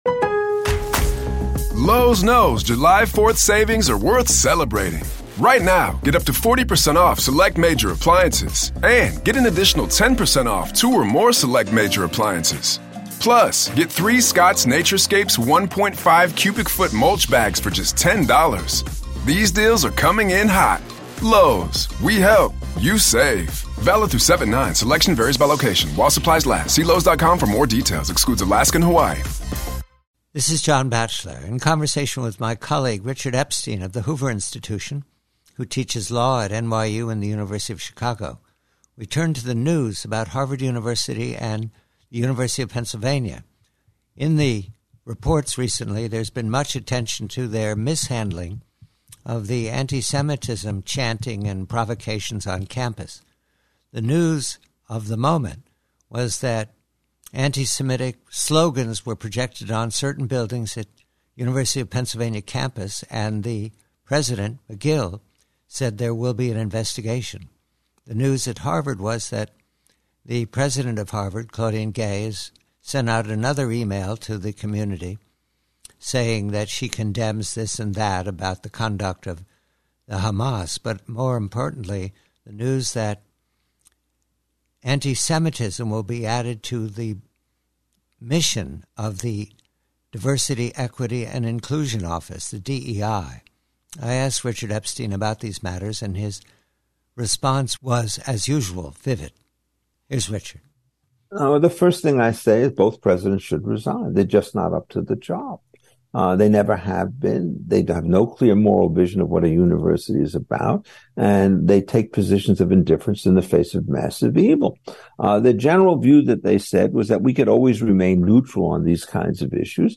PREVIEW: From a longer conversation, Professor Richard Epstein observes the struggles and failures of two elite universities, Harvard University and University of Pennsylvania, and he recommends complete renovation of their missions.